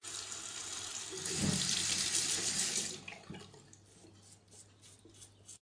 包装 " 剃须刀
描述：剃刀 ZOOM H6
标签： 胡须 浴室 剃须刀
声道立体声